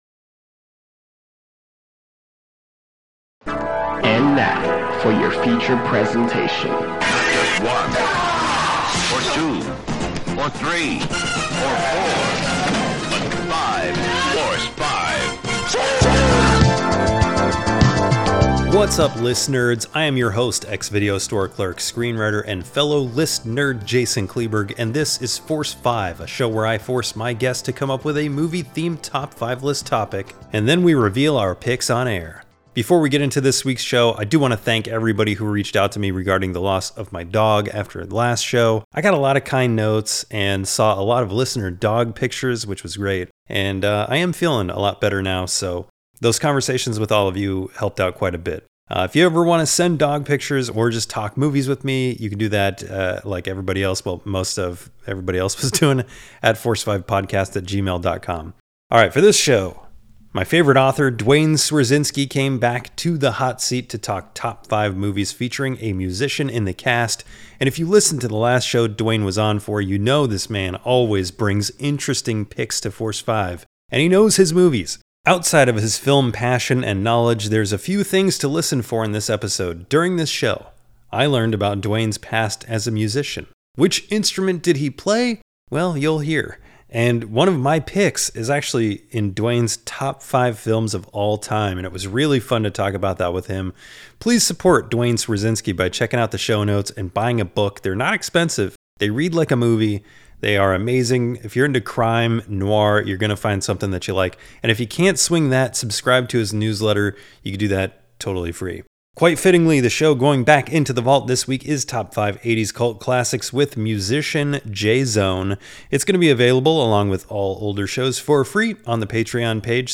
Each guest comes up with a movie-themed ‘five list’ topic beforehand, and then the picks are discussed on-air.